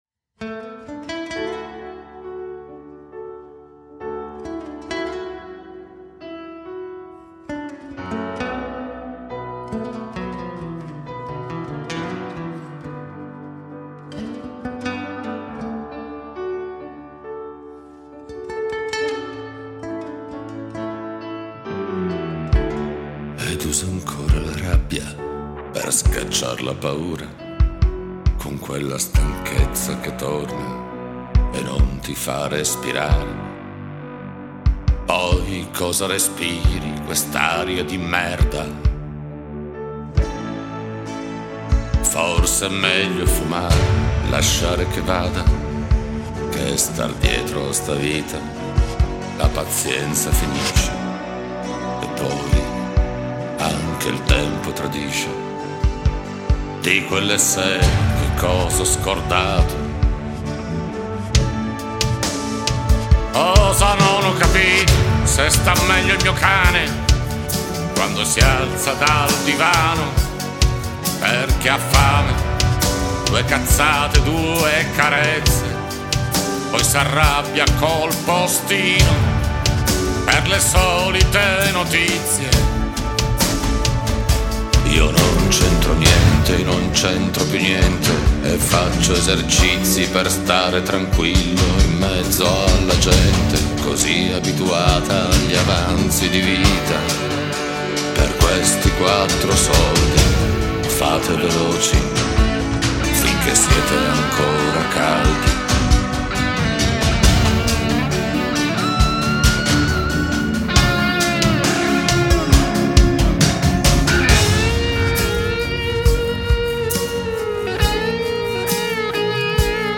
una band acustica
Lead Vocal
Guitars
Percussions
Bass